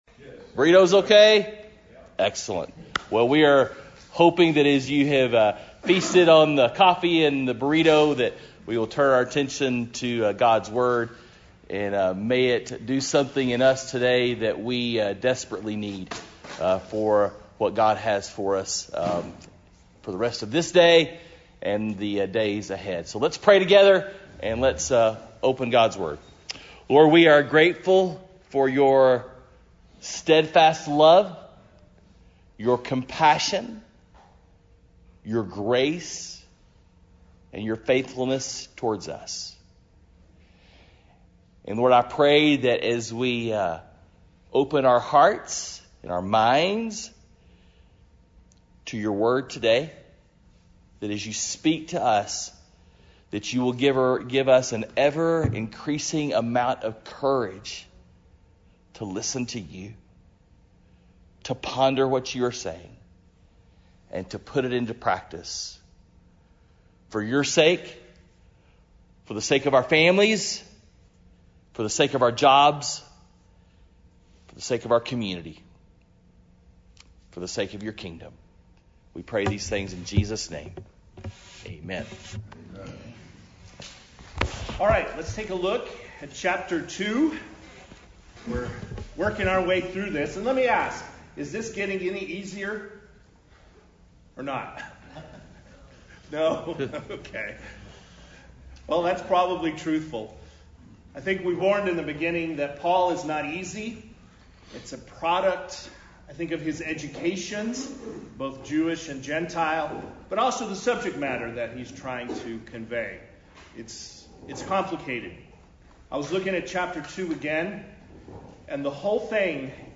Men’s Breakfast Bible Study 9/1/20